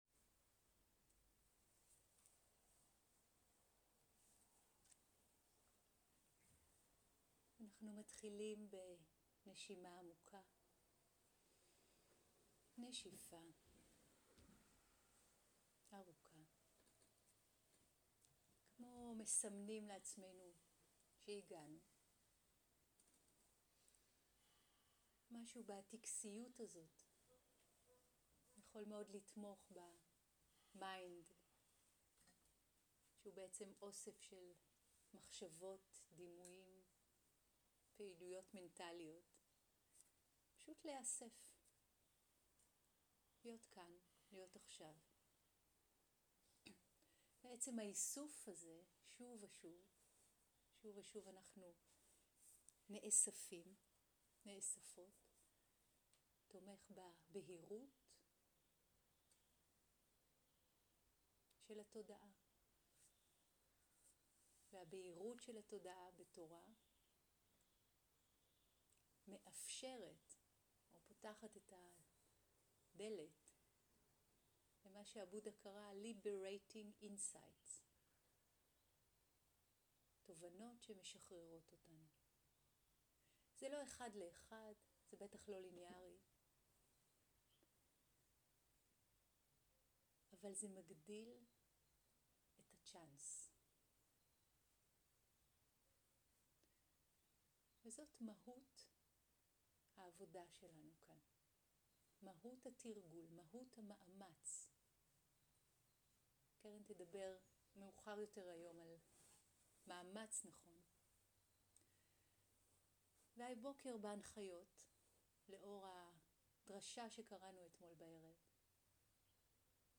סוג ההקלטה: שיחת הנחיות למדיטציה
עברית איכות ההקלטה: איכות גבוהה מידע נוסף אודות ההקלטה